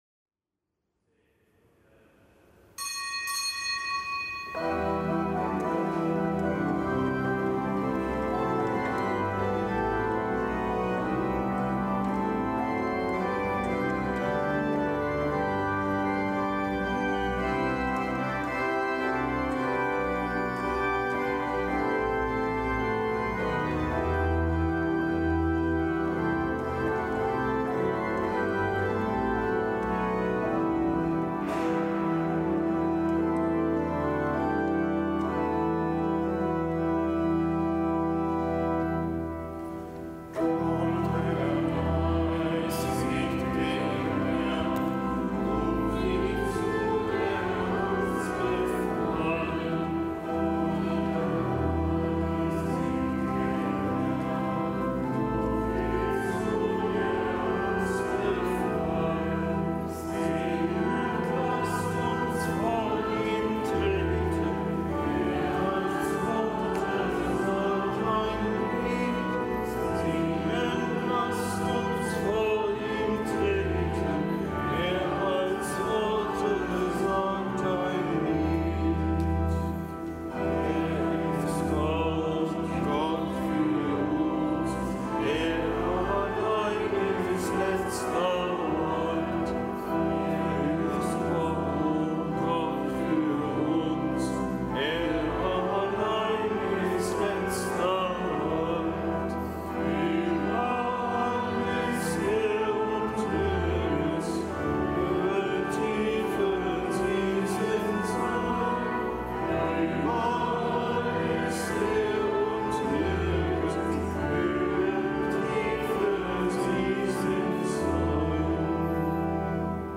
Kapitelsmesse am Gedenktag der Heiligen Marta, Maria und Lazarus
Kapitelsmesse aus dem Kölner Dom am Gedenktag der Heiligen Marta, Maria und Lazarus, Freunde Jesu.